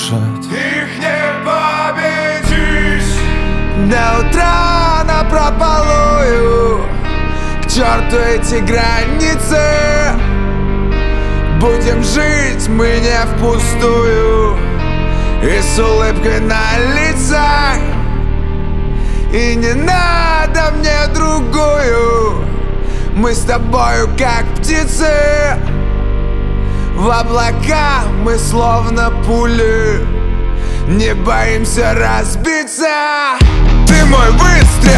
Pop Rock Pop